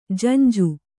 ♪ janju